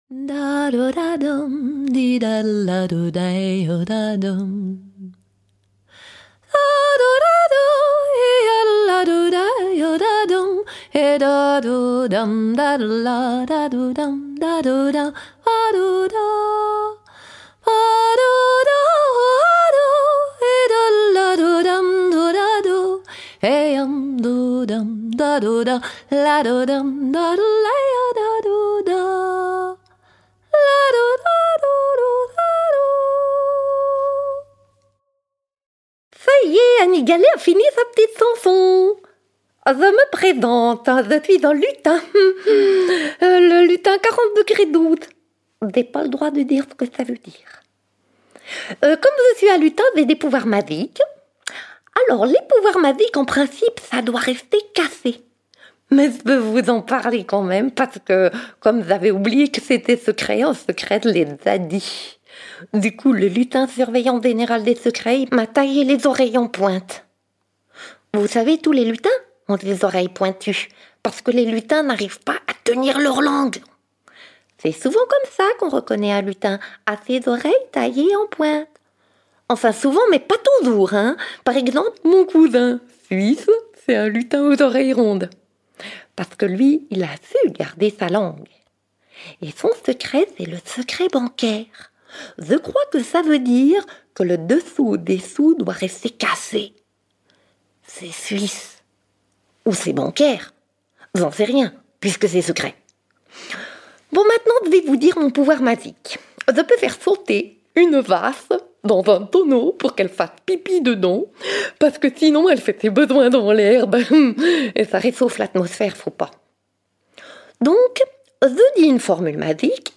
De Midas et ses oreilles d’âne au doigt de l’ange posé sur les lèvres du bébé, les histoires de secret nous emmènent du drame à la comédie, du trivial au divin… Les conteuses et conteurs tirent le voile sur cette passionnante et parfois douloureuse qualité humaine : garder un secret, le partager et parfois… le trahir !